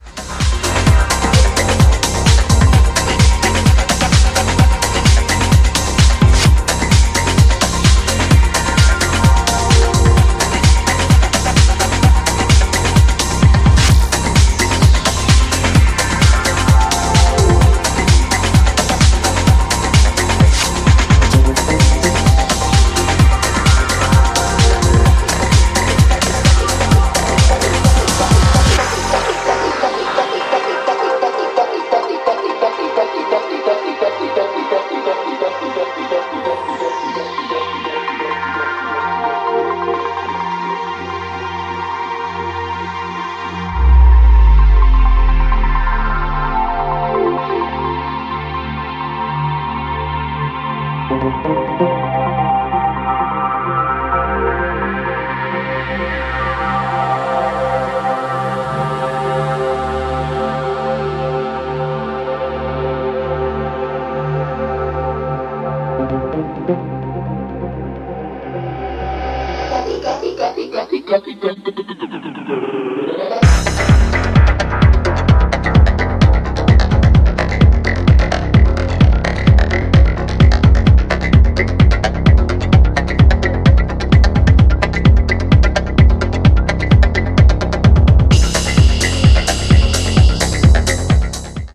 ジャンル(スタイル) HOUSE / TECHNO